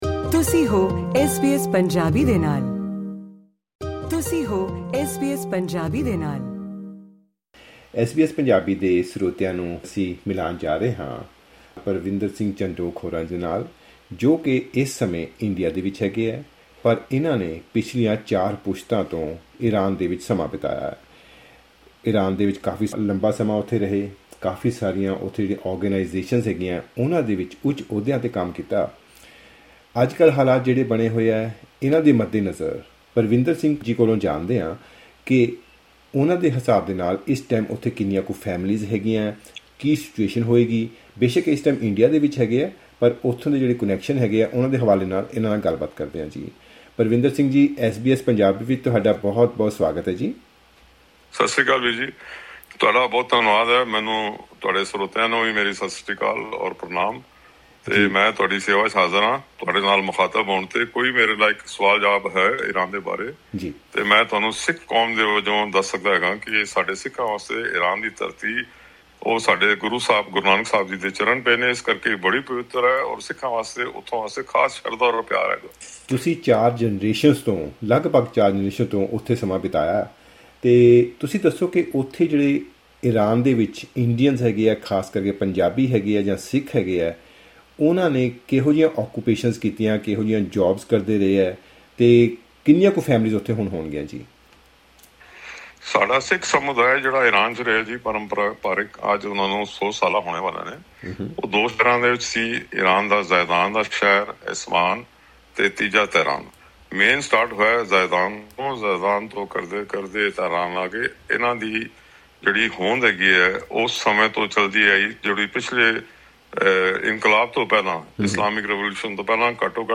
Click on the audio icon to listen to the full report and interview in Punjabi.